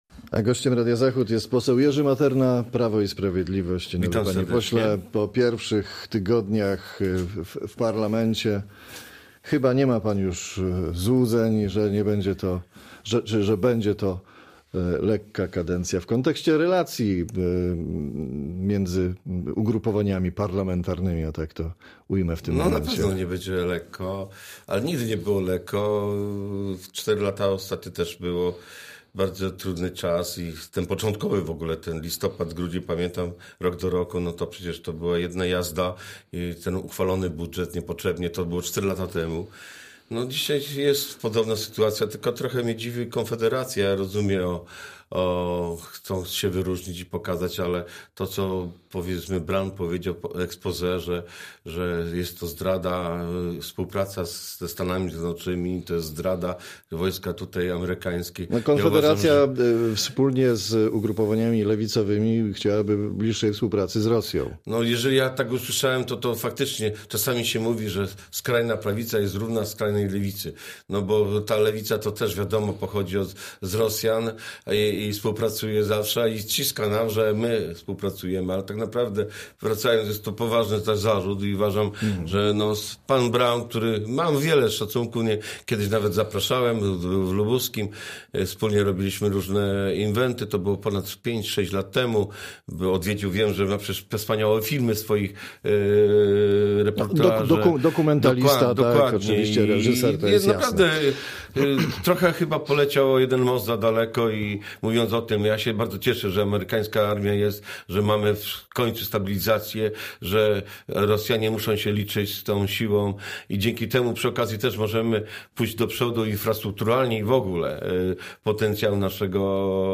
Z posłem PiS rozmawia